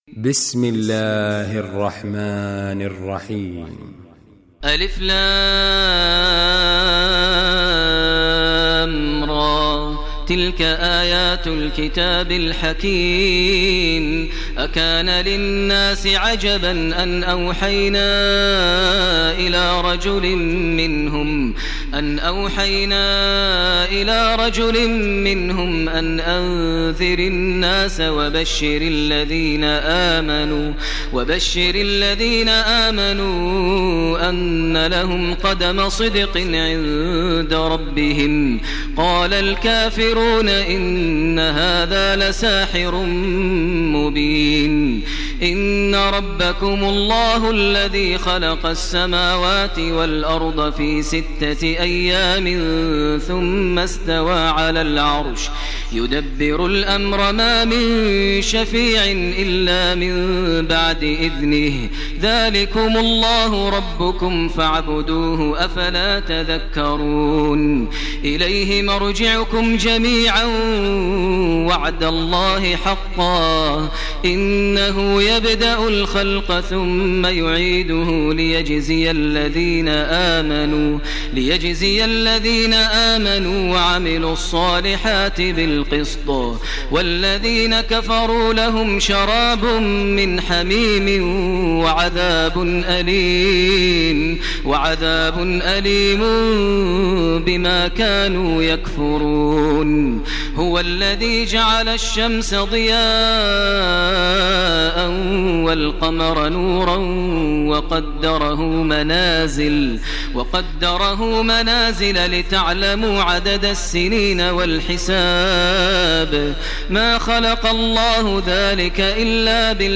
Surah Yunus Recitation by Maher al Mueaqly
Surah Yunus, listen online mp3 tilawat / recitation in Arabic in the voice of Sheikh Maher al Mueaqly.